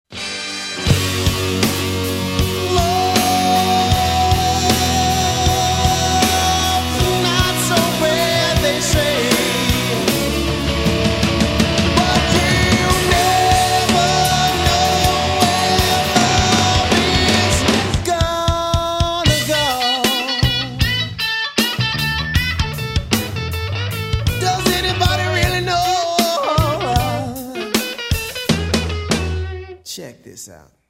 • Качество: 128, Stereo
мужской вокал
электрогитара
blues Rock
Funk Rock
Funk Metal